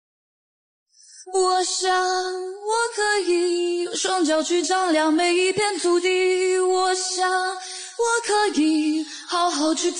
sing_female_rap_10s.MP3